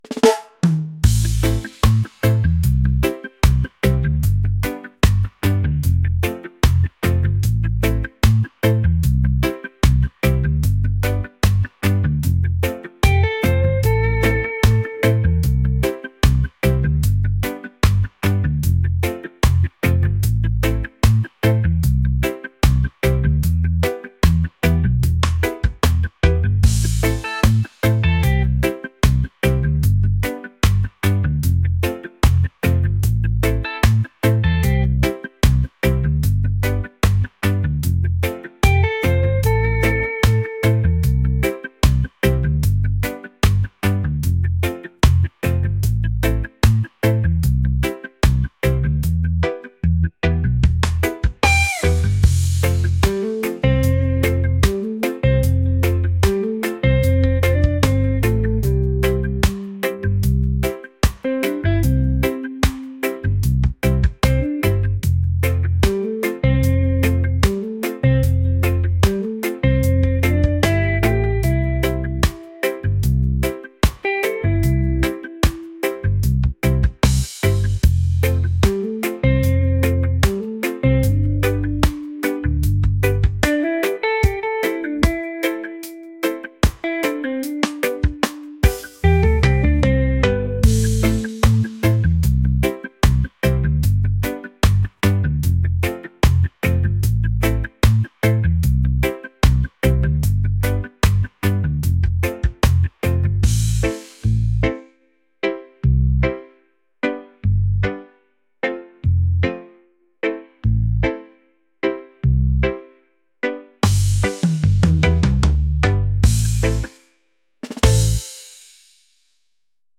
soul | laid-back | reggae